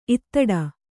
♪ ittaḍa